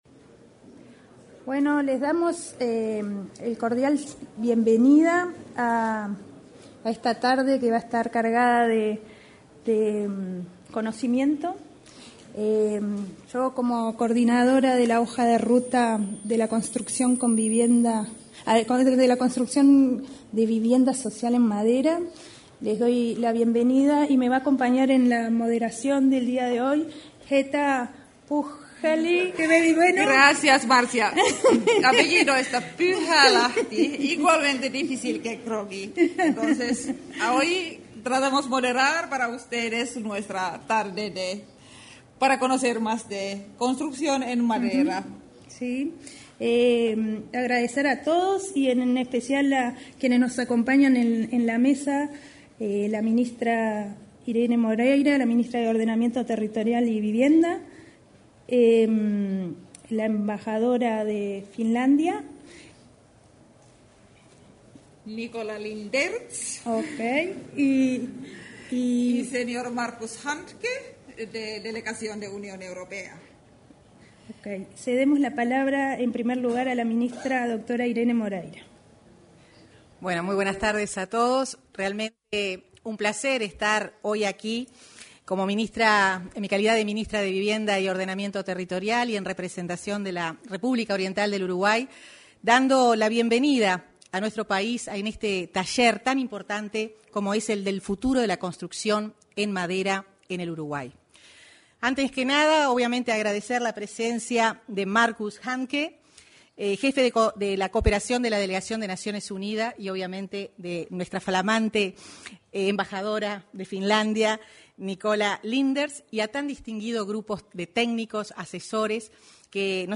En el salón de actos de Torre Ejecutiva tuvo lugar el Taller Futuro de la Construcción en Madera en Uruguay; se expresaron la ministra de Vivienda,